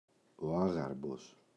άγαρμπος, ο [‘aγarmbos] – ΔΠΗ